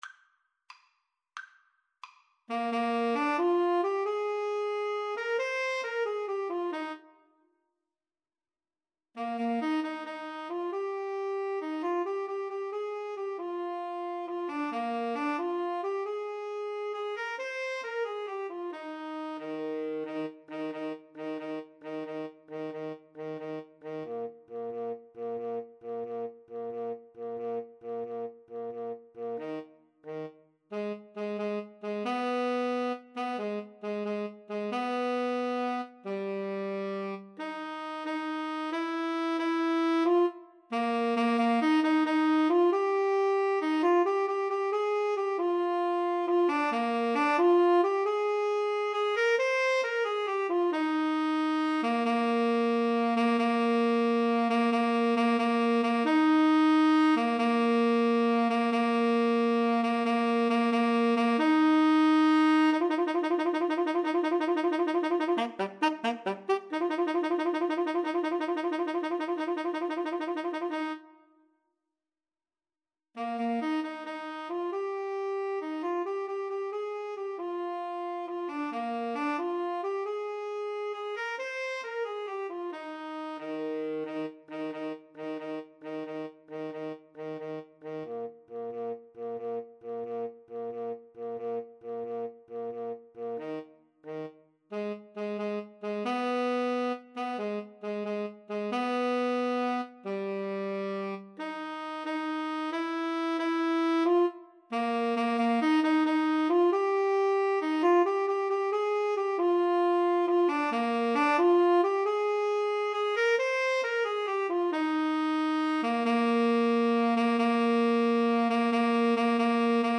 Tenor Sax 1Tenor Sax 2
Eb major (Sounding Pitch) (View more Eb major Music for Tenor Sax Duet )
6/8 (View more 6/8 Music)
Rollicking . = c. 90